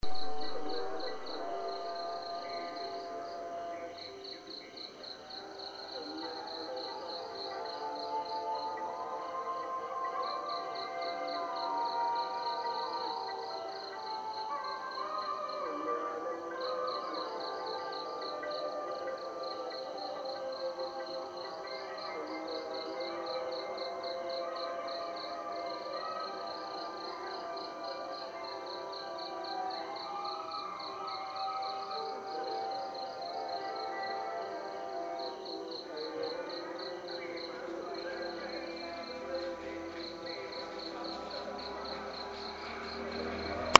Большинство жителей Бекала — мусульмане, но есть и два индуистских храма (один из них на фото, это, кстати, не древний, а свежевыстроенный храм, а из другого храма доносятся звуки, которые мы слушали по ночам, а вы слышите в этом эфире)